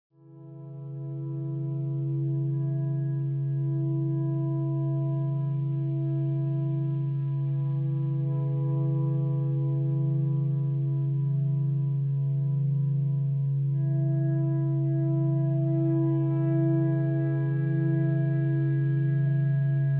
sledovat novinky v oddělení Experimentální hudba